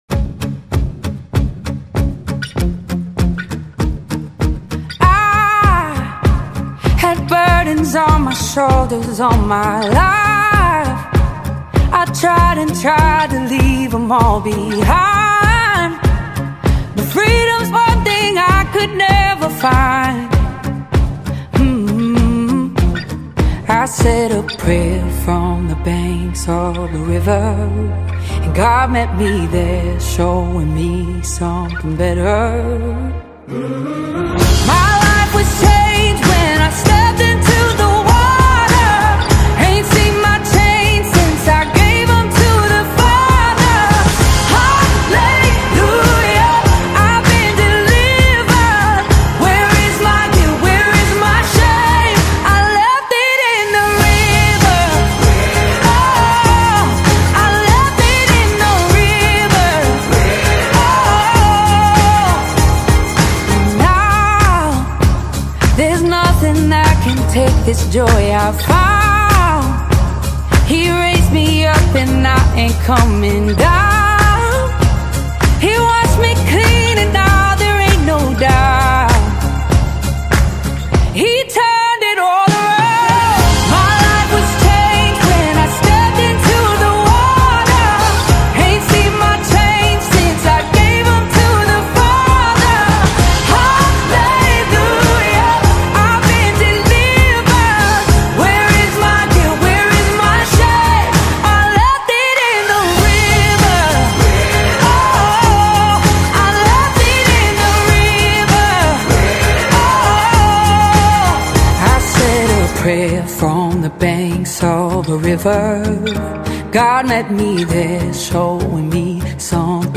From the gentle strumming
warm, emotive vocals
Gospel Songs